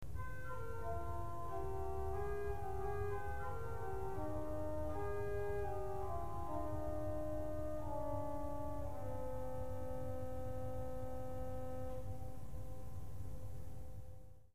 Salicet, Salicjonał
Nazwa głosu pochodzi od łacińskiego słowa salix, czyli wierzba. Jest to głos smyczkujący, wprowadzony do organów w XVII wieku, budowany przeważnie z metalu. Posiada cylindryczny kształt korpusu, budowany jest w wersjach 8', 4' i 2', w pedale występuje jako salicetbas 16'.